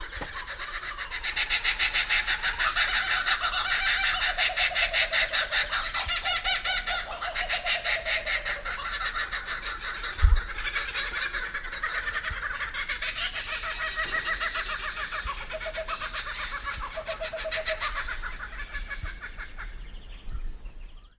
Mein Lieblingsvogel, ein Kookaburra (Myall Lakes National Park, Australien)
Kookaburra.mp3